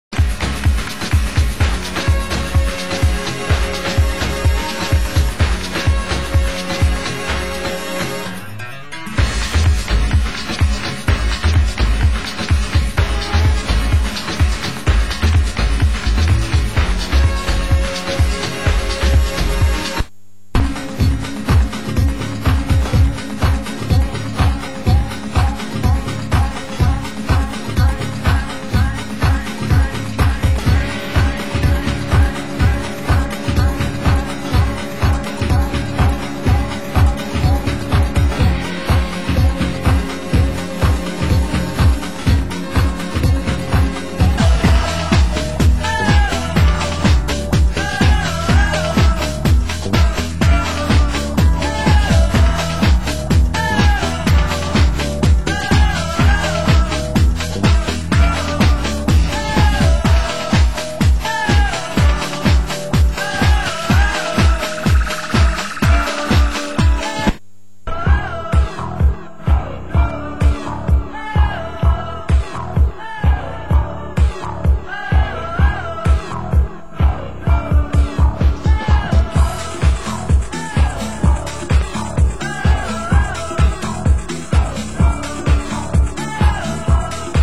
Format: Vinyl 12 Inch
Genre: UK House
Keyboards